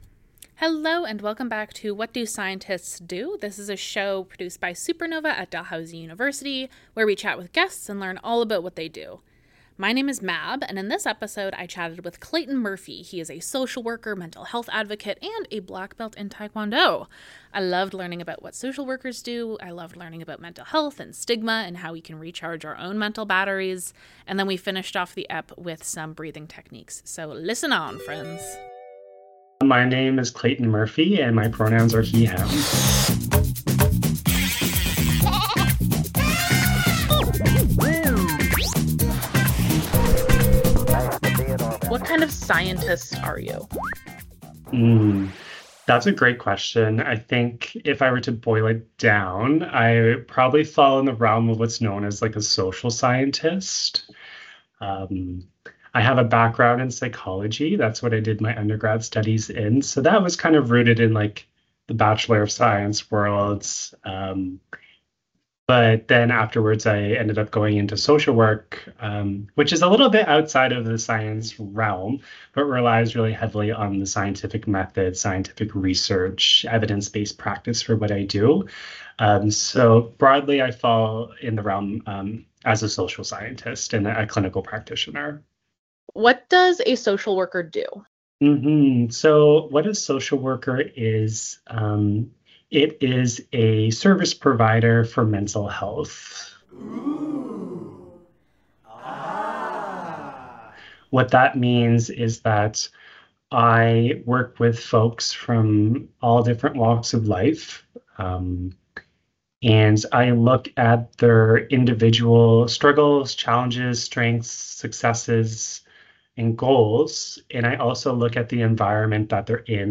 In each episode, a guest expert teaches us all about a new science, technology, engineering, or math topic. How do we study things that are too small to see with our eyes?